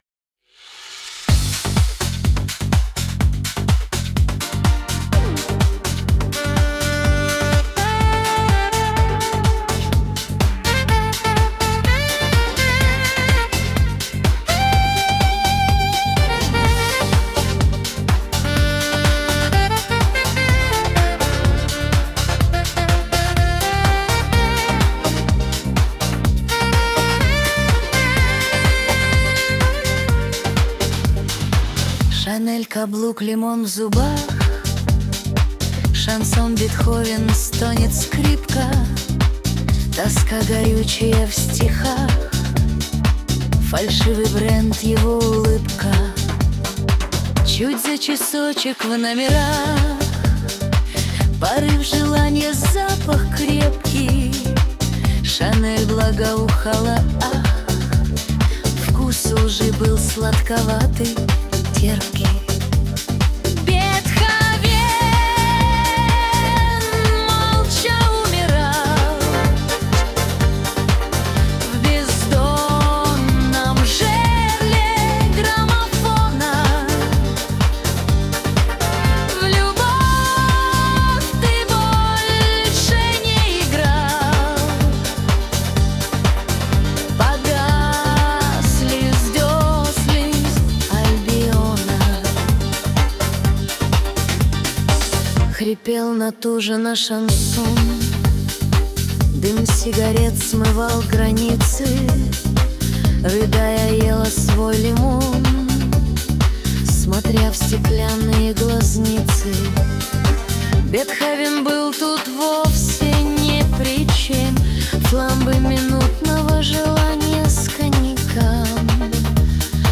Несмотря на столь множественные знания в разных областях, он ударения ставит странновато...)))
Попсу от ИИ слушать не стал.